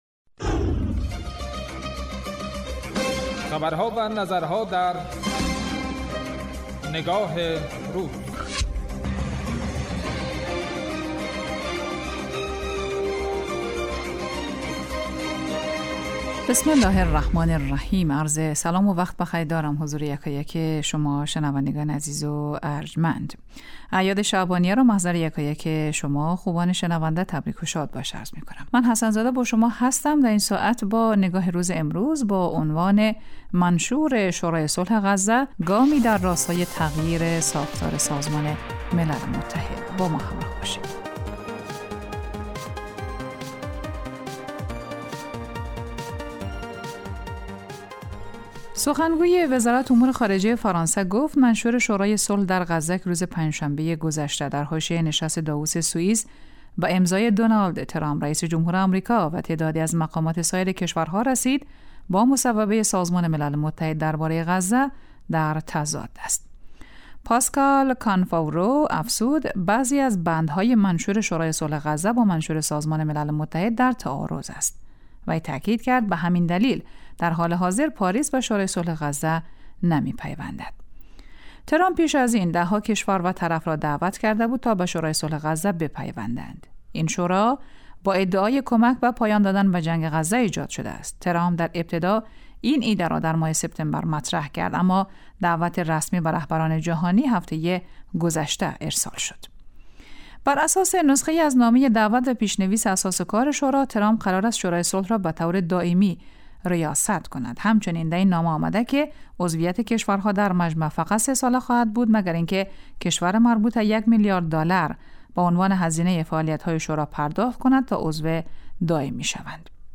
برنامه تحلیلی نگاه روز از شنبه تا پنجشنبه راس ساعت 14 به مدت 10 دقیقه پخش می گردد